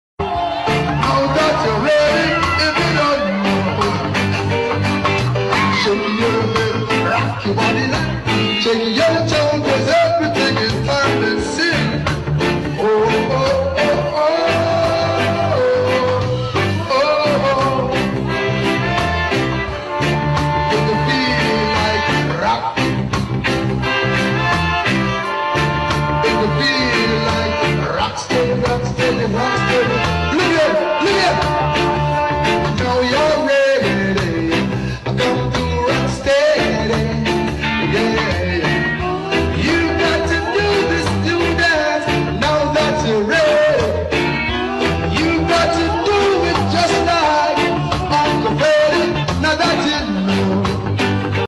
Full of vibes, soul, and charisma.